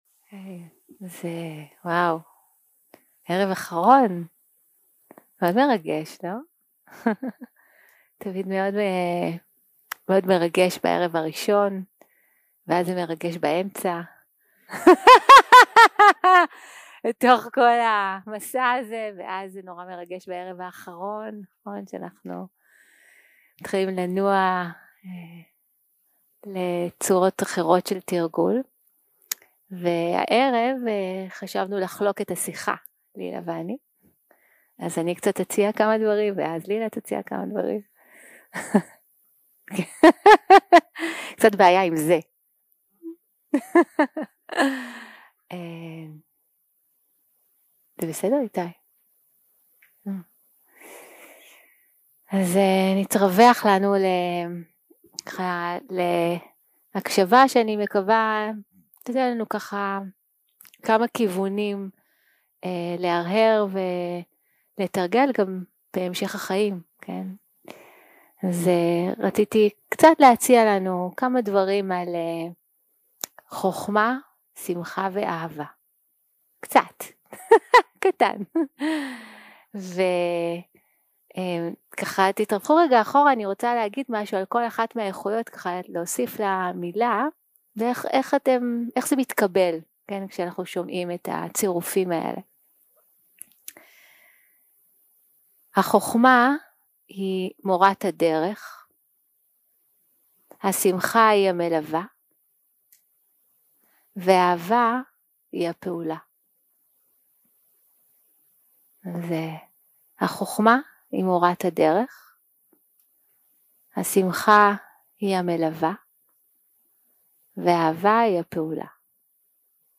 יום 7 - הקלטה 17 - ערב - שיחת דהרמה